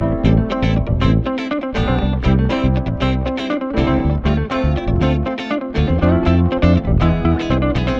31 Backing No Brass PT1.wav